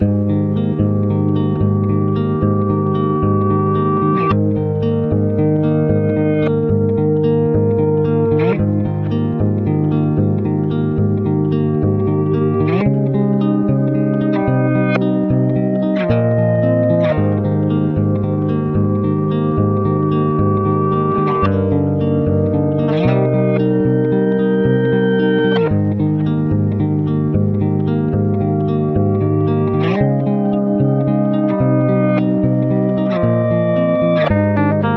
Жанр: Альтернатива